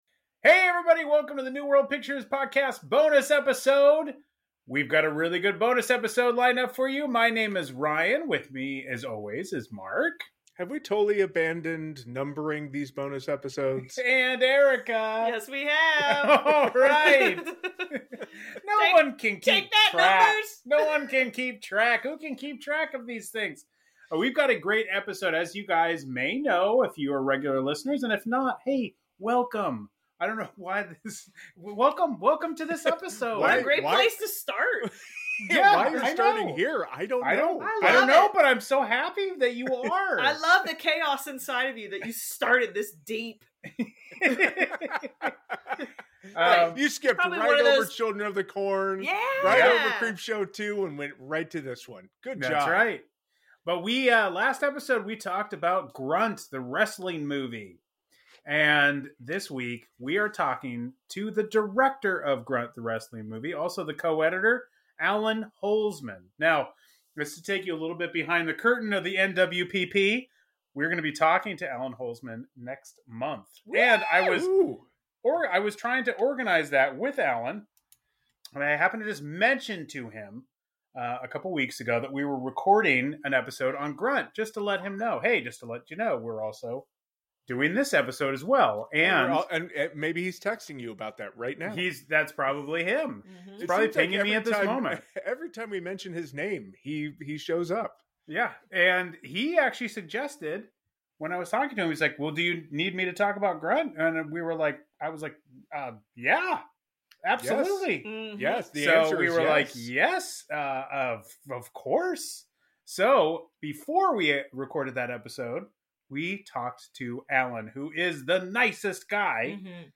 Bonus Episode: Interview